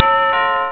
1 channel
snd_17455_Doorbell.wav